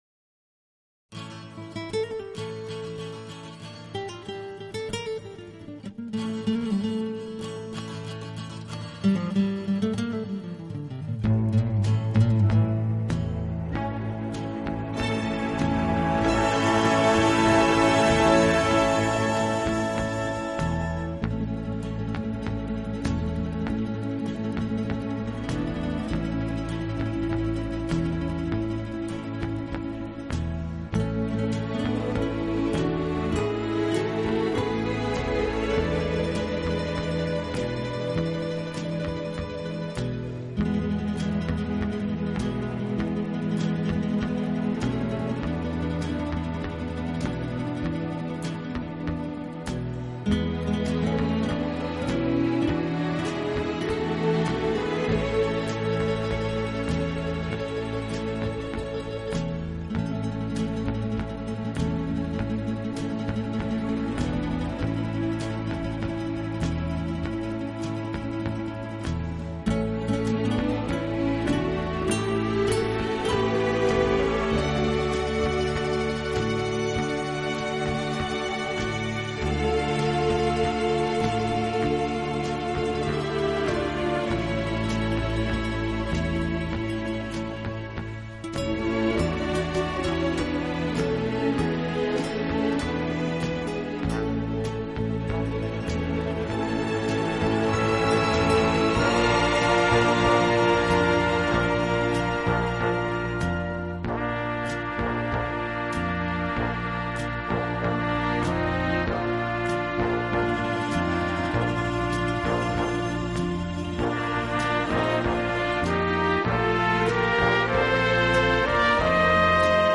【轻音乐】
小号的音色，让他演奏主旋律，而由弦乐器予以衬托铺垫，音乐风格迷人柔情，声情并
温情、柔软、浪漫是他的特色，也是他与德国众艺术家不同的地方。